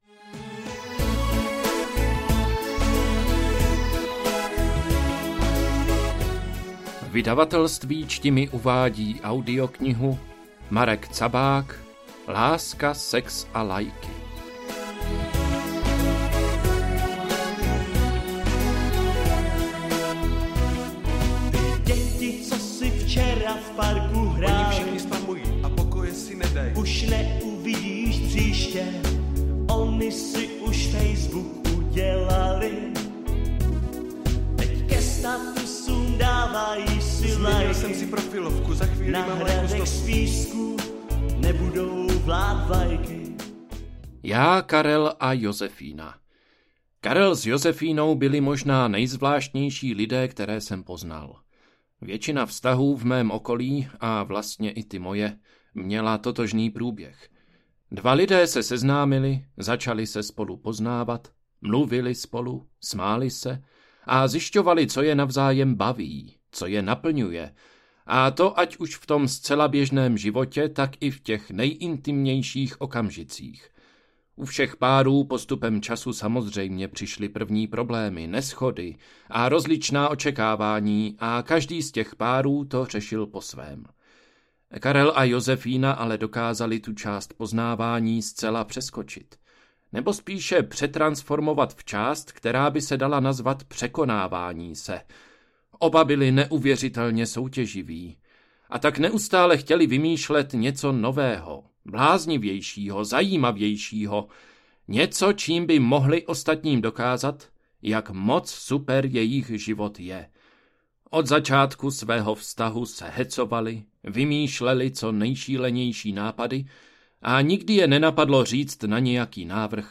AudioKniha ke stažení, 24 x mp3, délka 8 hod. 14 min., velikost 475,1 MB, česky